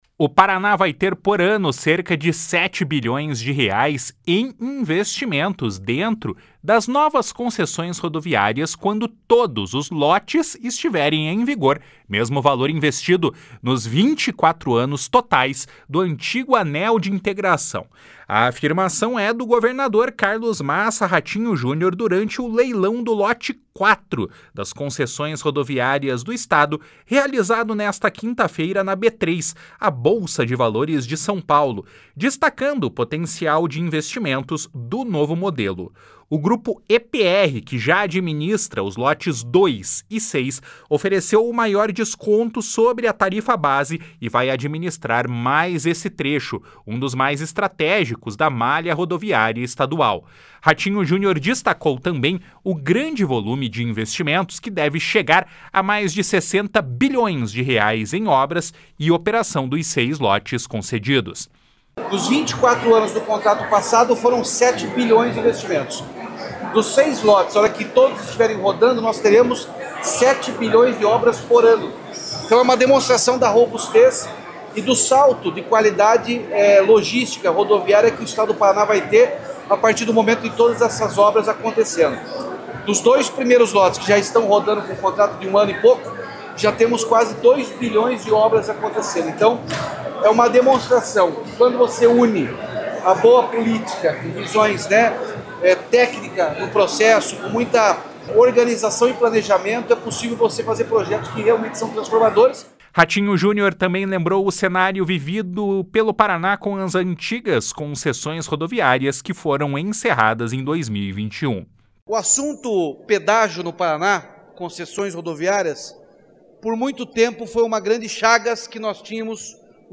// SONORA RATINHO JUNIOR //
// SONORA RENAN FILHO //